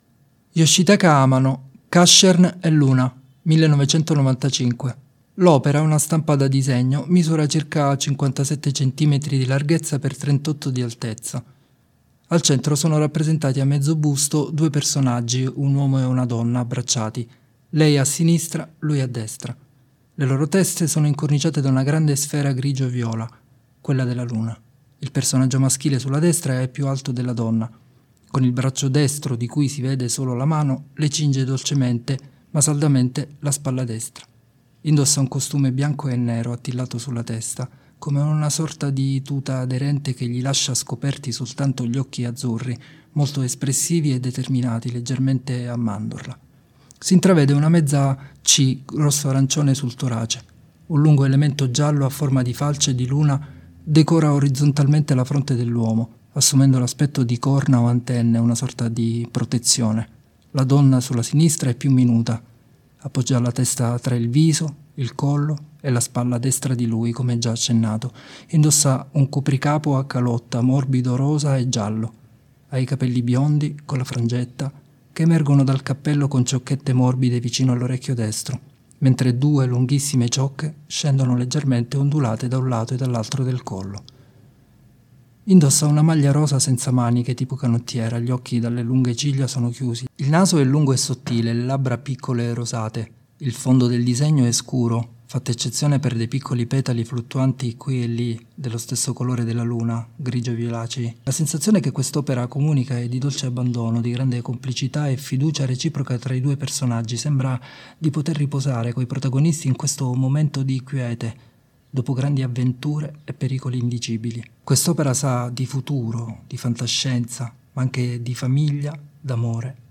Descrizioni pannelli sensoriali per ciechi e ipo-vedenti: